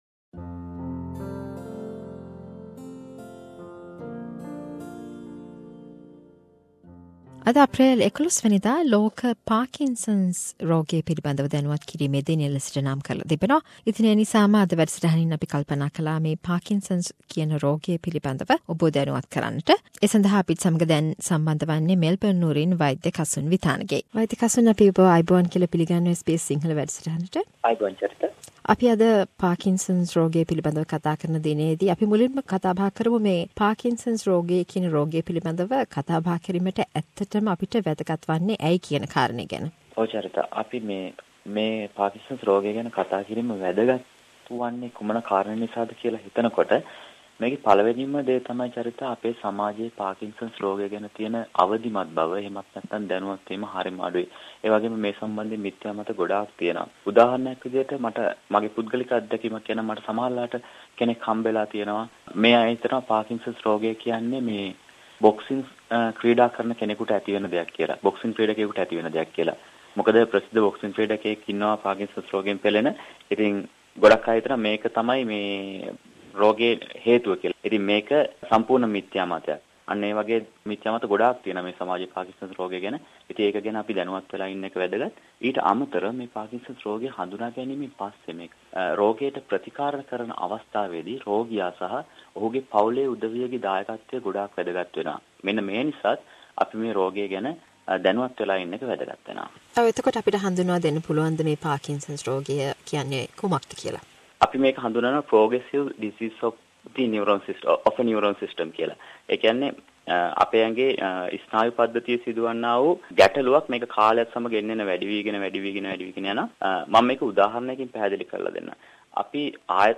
A medical discussion on Parkinsosn's disease